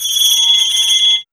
2405R BELLS.wav